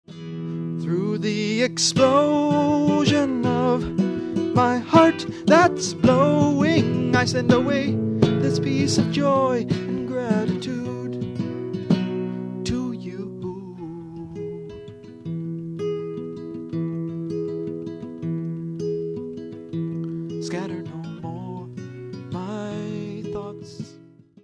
Songs on Guitar & Piano 8-12-78 (Double LP length)
2-track cassette original master